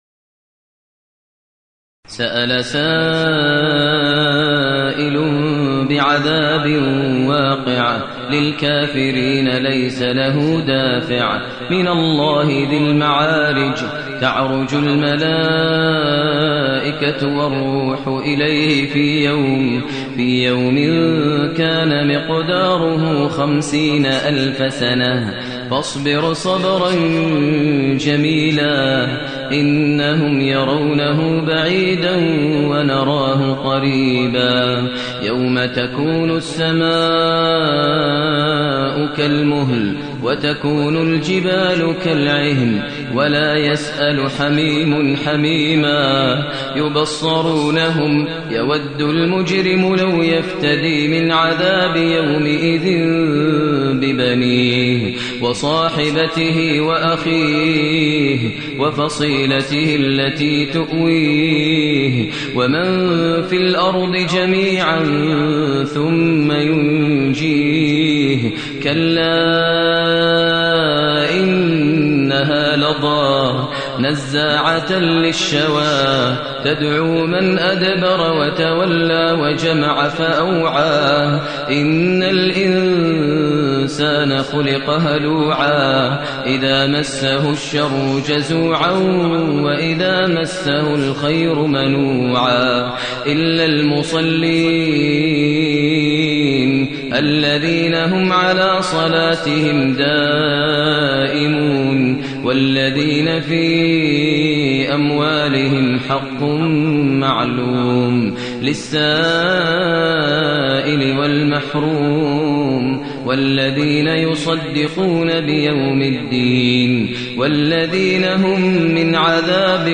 المكان: المسجد النبوي الشيخ: فضيلة الشيخ ماهر المعيقلي فضيلة الشيخ ماهر المعيقلي المعارج